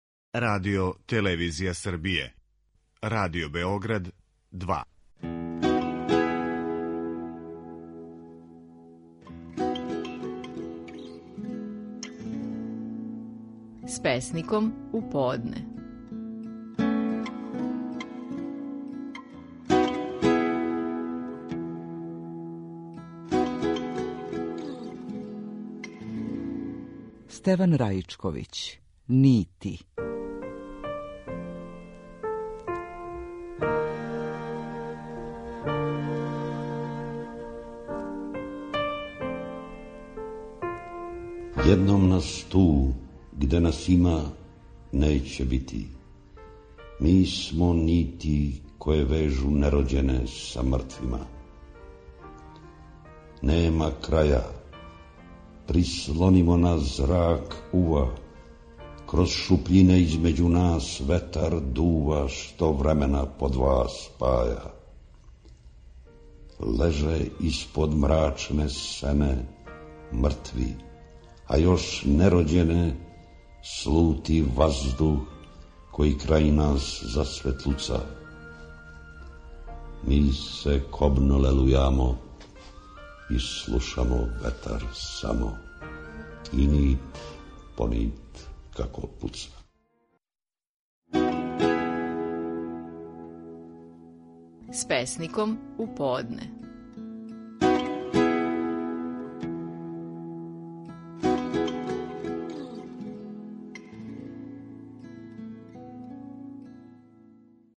Стихови наших најпознатијих песника, у интерпретацији аутора.
Стеван Раичковић говори своју чувену песму „Нити".